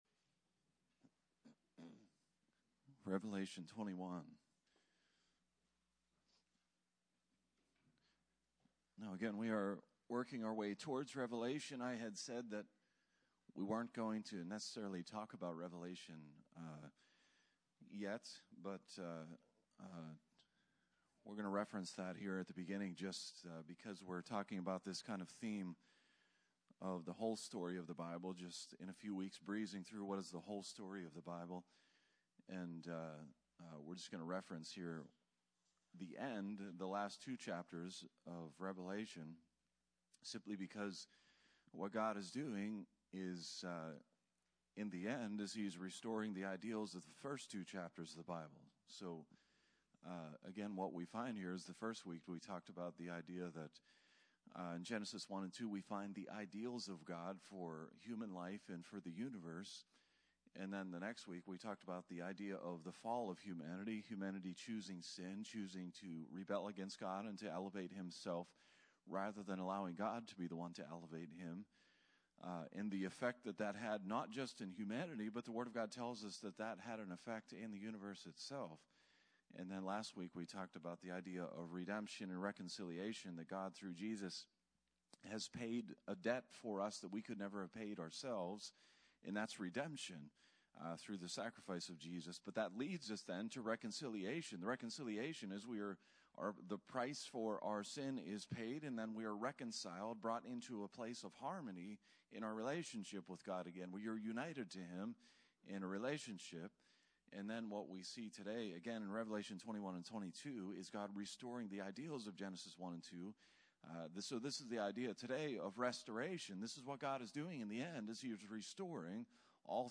Sermons | Barrs Mill Church of God